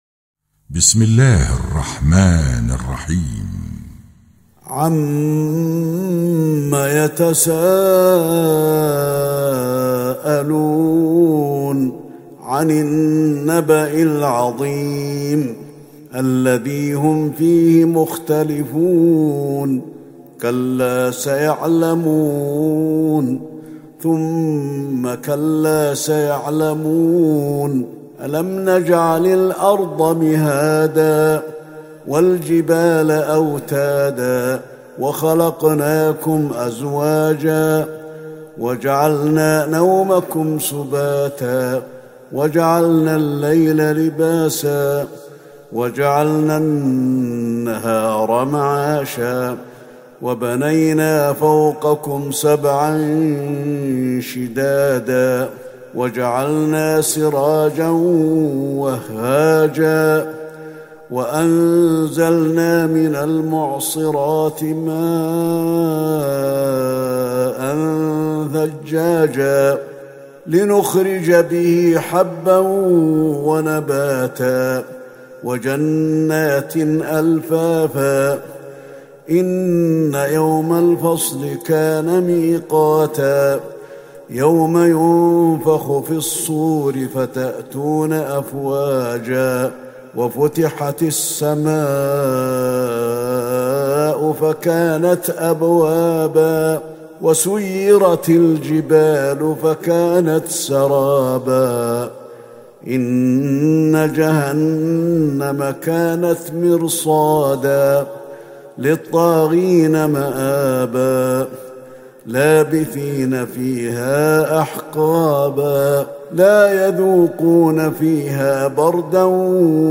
تهجد ٢٩ رمضان ١٤٤١هـ من سورة النبأ إلى عبس > تراويح الحرم النبوي عام 1441 🕌 > التراويح - تلاوات الحرمين